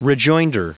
Prononciation du mot rejoinder en anglais (fichier audio)
Prononciation du mot : rejoinder